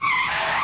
BreakSqueal.wav